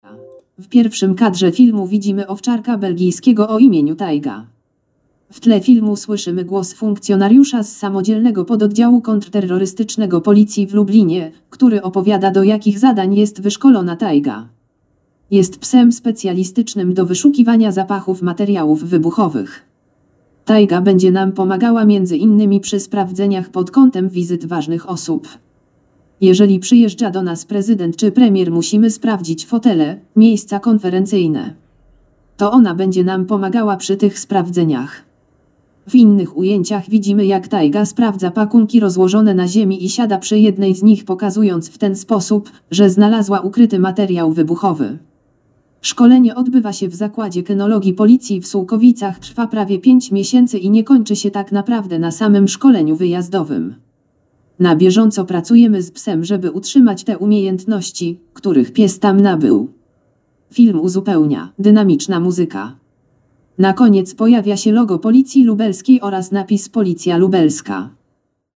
Nagranie audio Audiodeskrypcja filmu. Szkolenie policyjnych psów z udziałem „Tajgi”